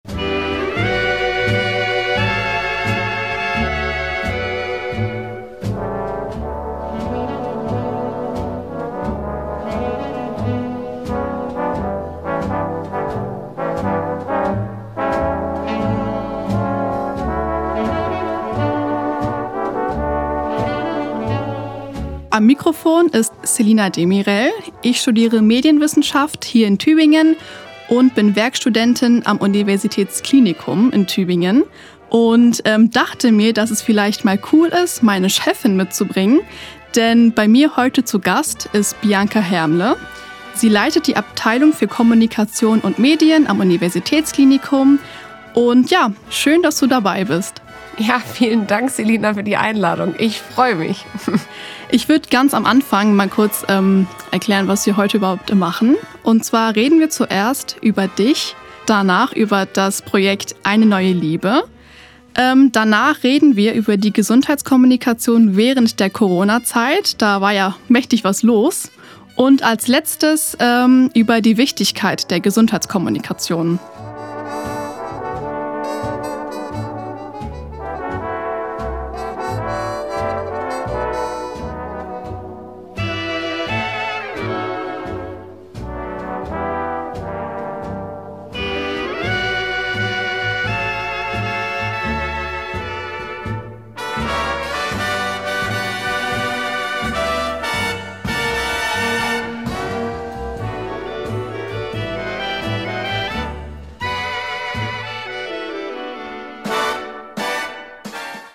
Live-Übertragungen, Regionales, Soziales